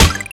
stop_hit.wav